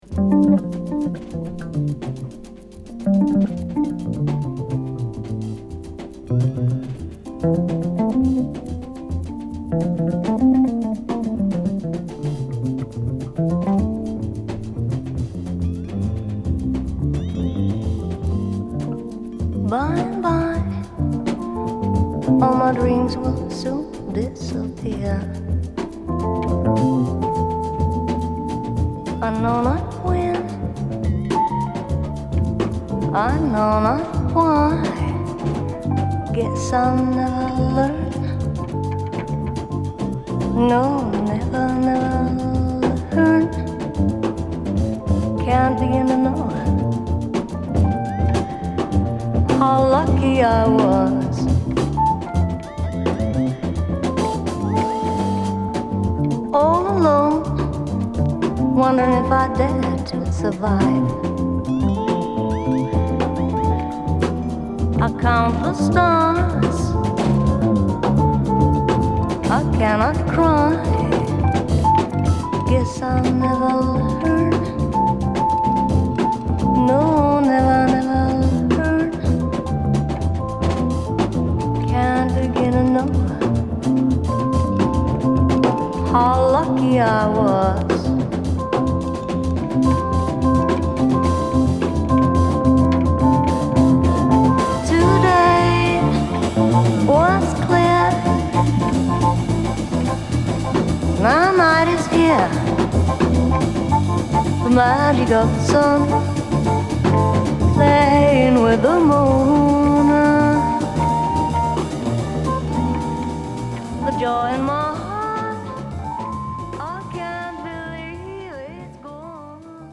アルバム通してアグレッシブな演奏と透明感／浮遊感あるヴォーカルが絡む傑作！
A1途中でプチノイズ有ります。